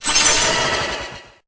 Cri_0826_EB.ogg